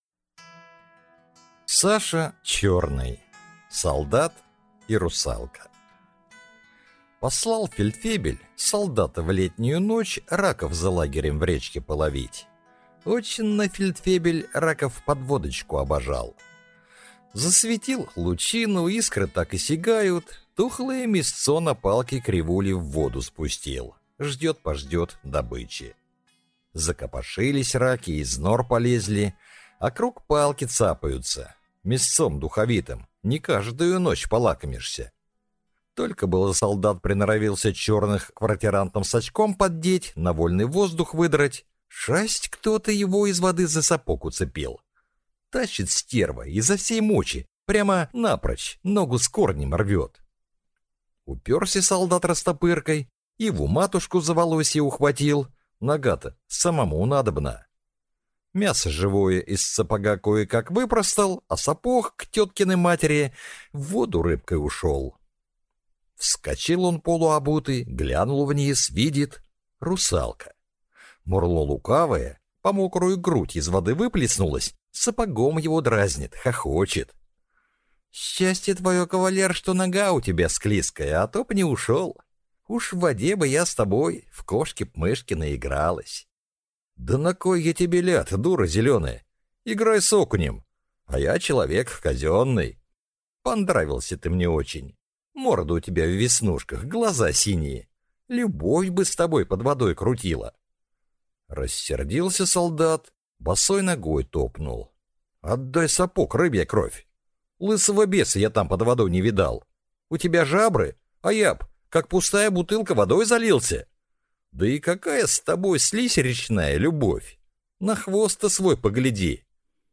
Аудиокнига Солдат и русалка | Библиотека аудиокниг
Aудиокнига Солдат и русалка